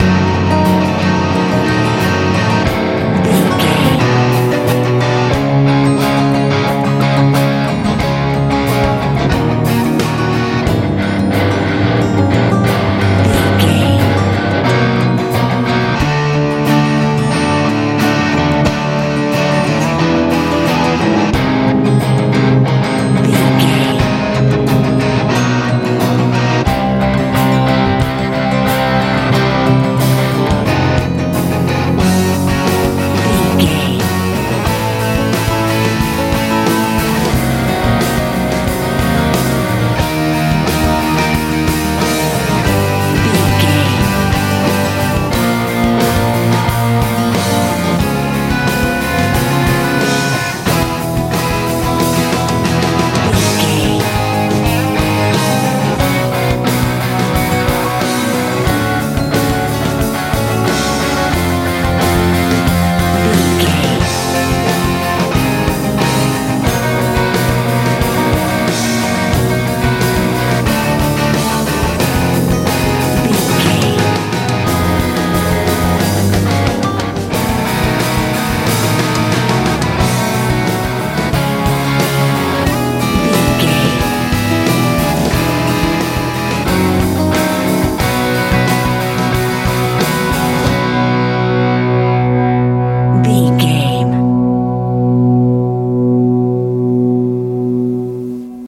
healing rock
Ionian/Major
E♭
electric guitar
acoustic guitar
drums
bass guitar
energetic
heavy